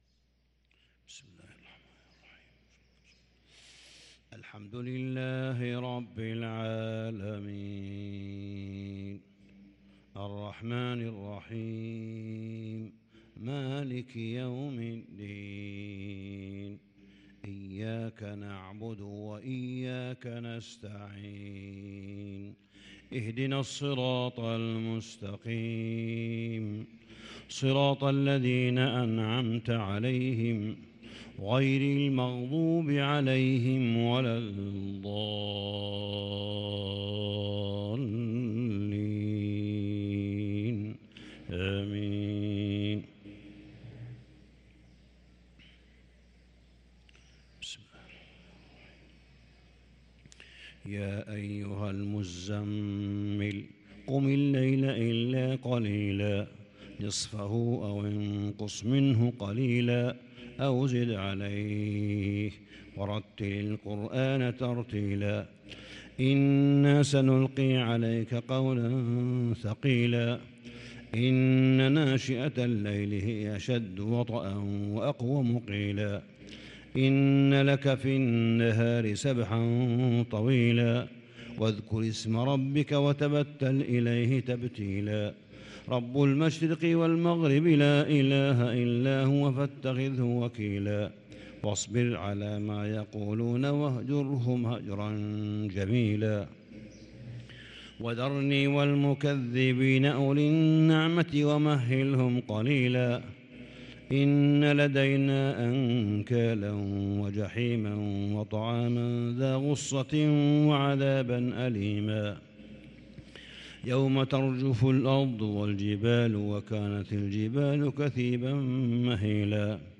صلاة الفجر للقارئ صالح بن حميد 29 شعبان 1443 هـ